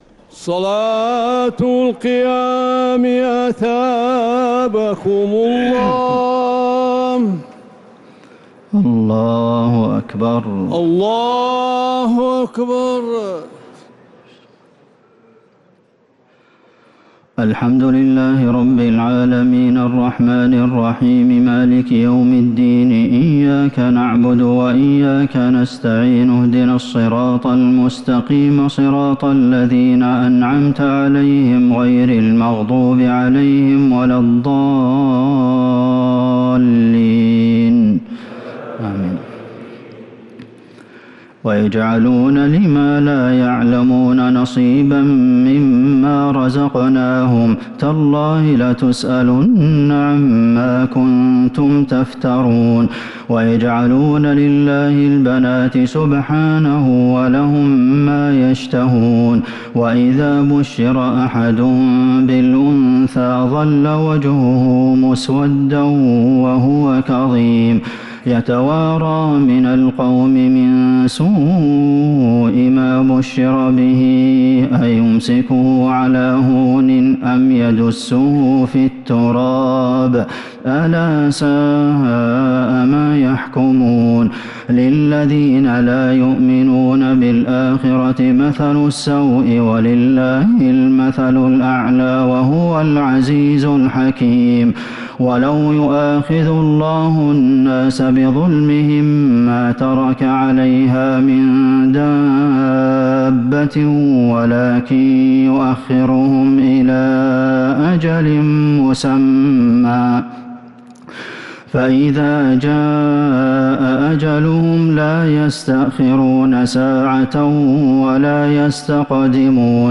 تراويح ليلة 19 رمضان 1447هـ من سورة النحل (56-128) | taraweeh 19th night Ramadan 1447H Surah An-Nahl > تراويح الحرم النبوي عام 1447 🕌 > التراويح - تلاوات الحرمين